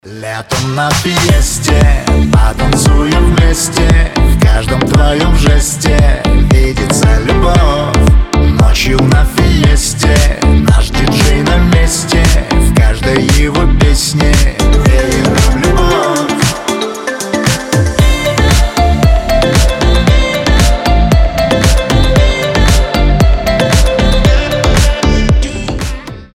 • Качество: 320, Stereo
позитивные
диско
заводные